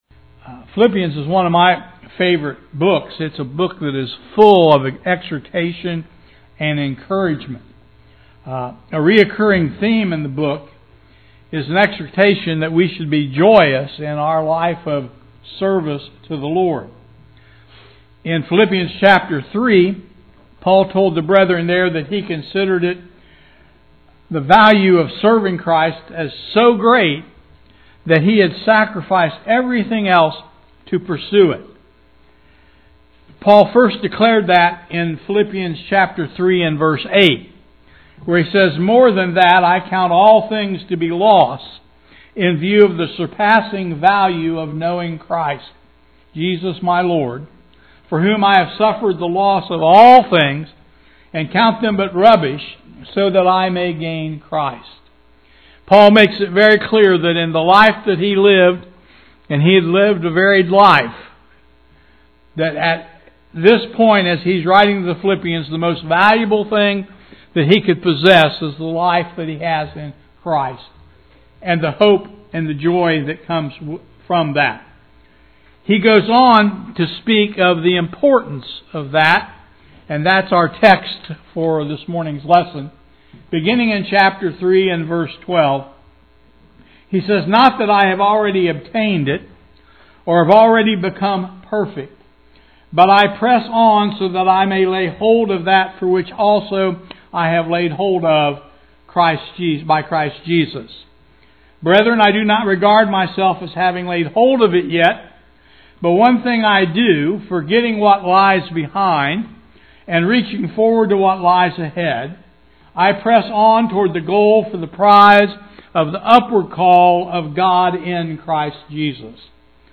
Visiting preacher